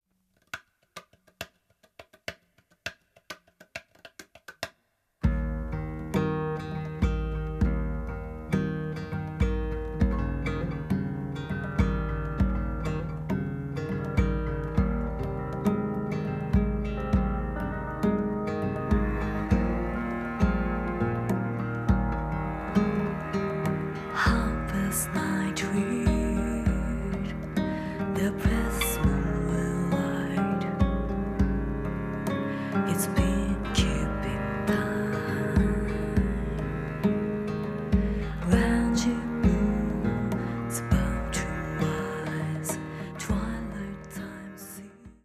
深みのあるギター・サウンド。囁くような歌声に呼び起こされるのはいつかの思い出。